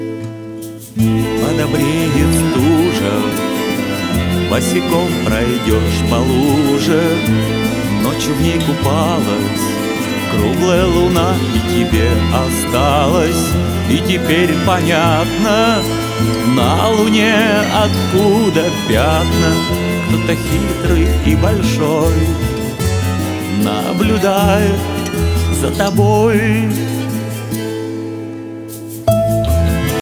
спокойные
скрипка
лирические
фолк-рок